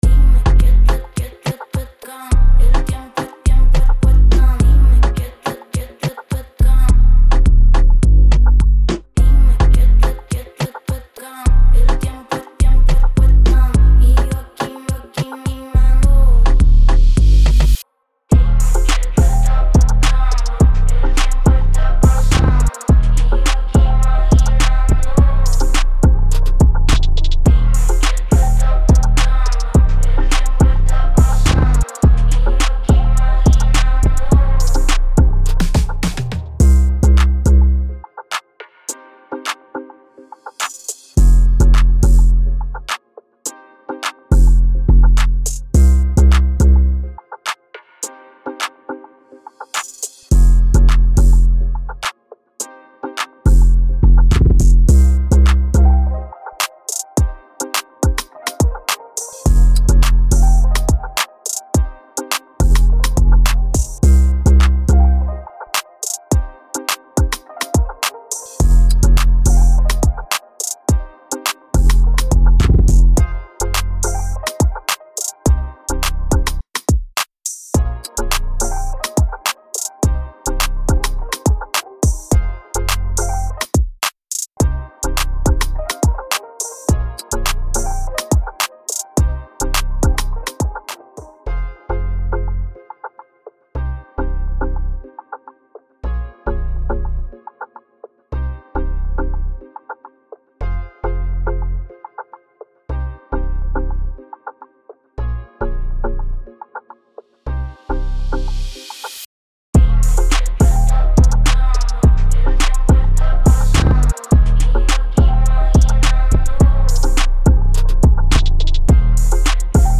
Beats
Key: C minor • BPM: 105